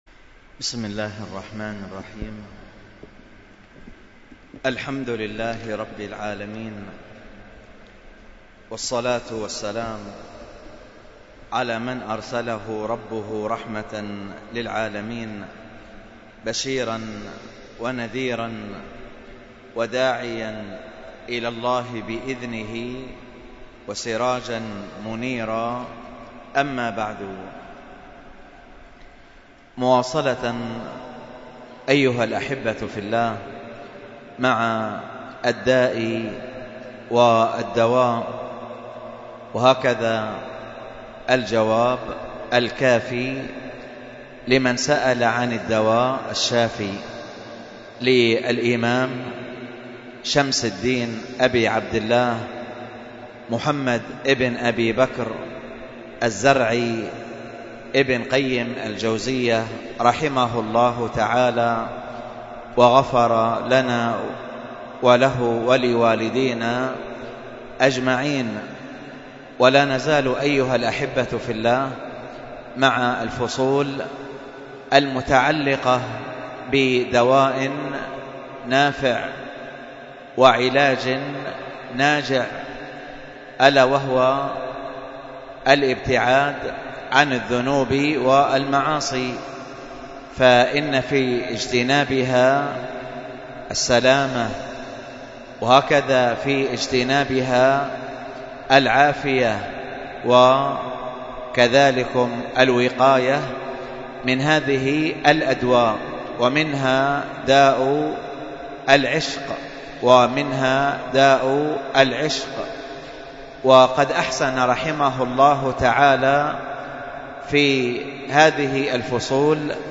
الدرس في التعليق على مذكرة أحكام الصيام 37، ألقاها